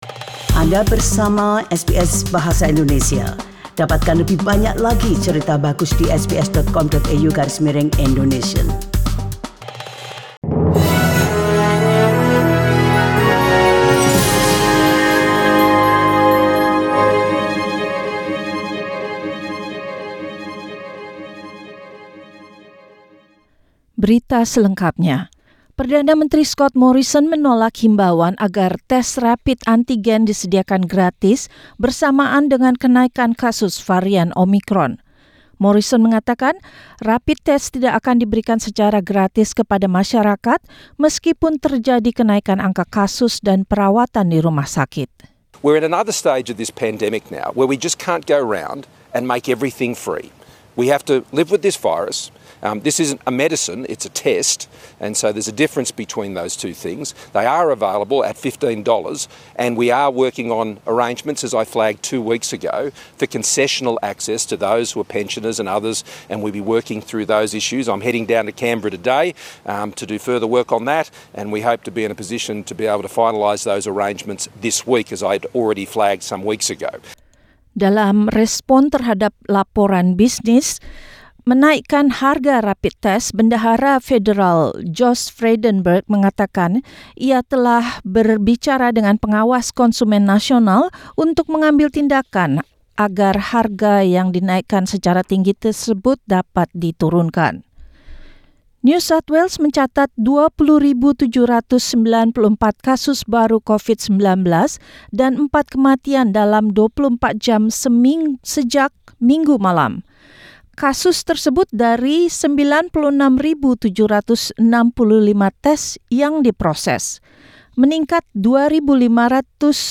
SBS Radio News in Indonesian, Monday, 3 January 2022